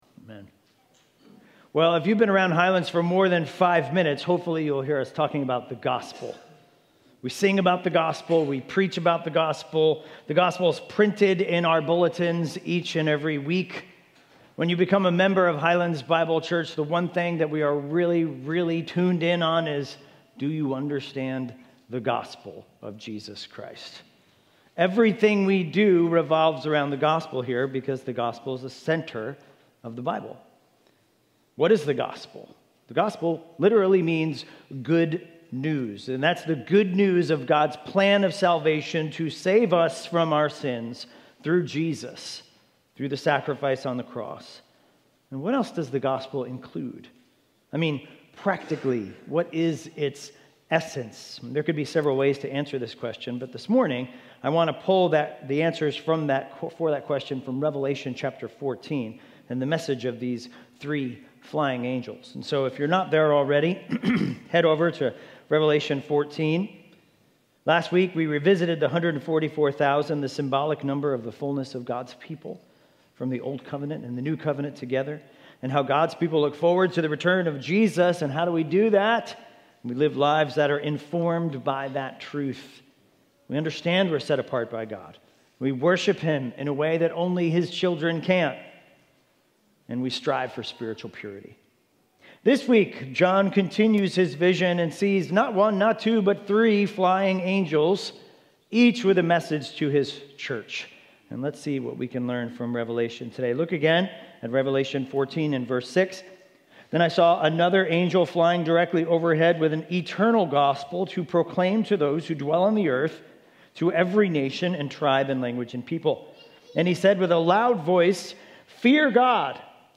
Highlands Bible Church Sermon Audio